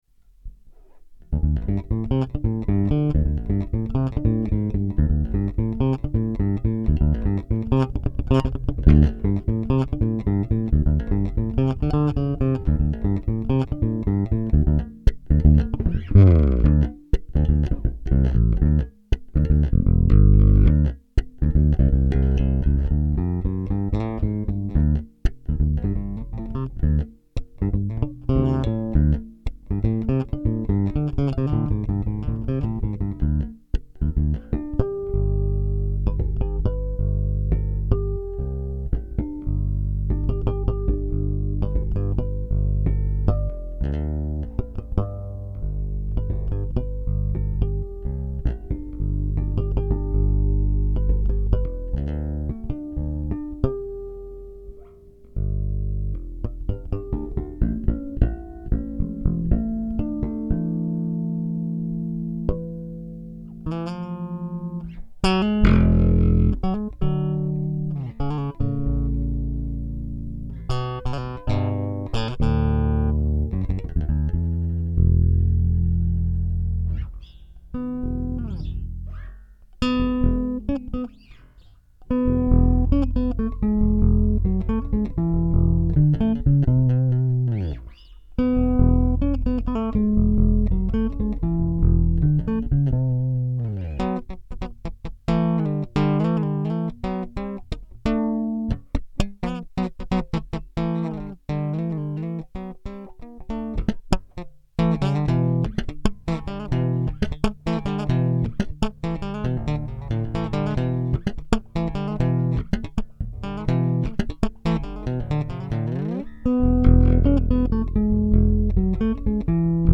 Por cierto, está grabado sin compresor ni hostias, directamente a la tarjeta.